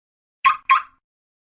carkeys.ogg